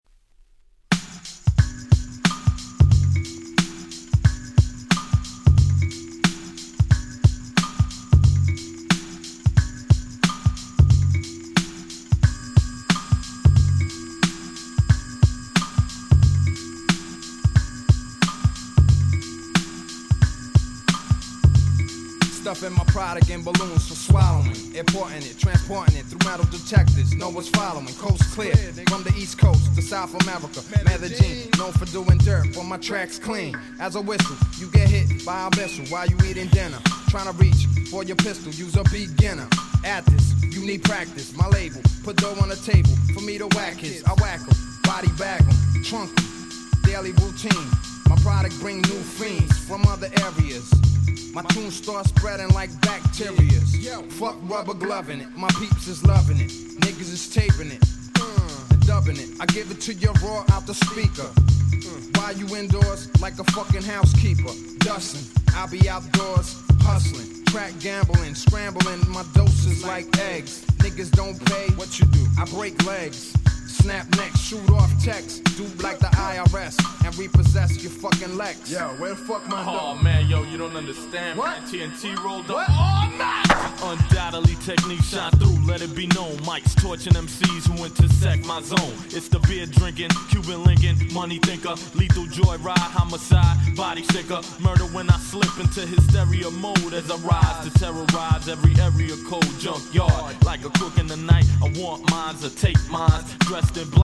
96' Nice Hip Hop !!
未だに色あせる事のない、「これぞ90's Hip Hop !!」な1曲です。